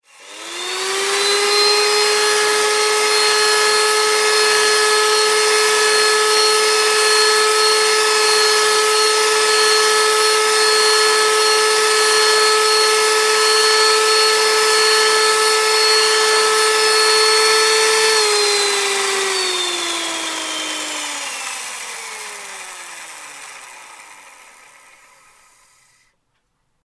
دانلود آهنگ مخلوط کن از افکت صوتی اشیاء
جلوه های صوتی
دانلود صدای مخلوط کن از ساعد نیوز با لینک مستقیم و کیفیت بالا